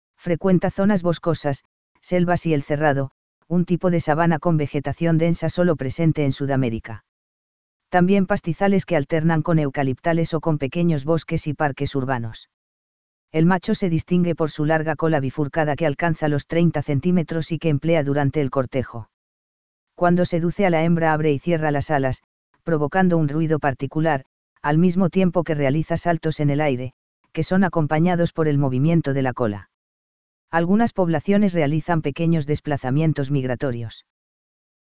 Hydropsalis torquata (brasiliana furcifera) - Dormilón tijereta
dormilontijereta.mp3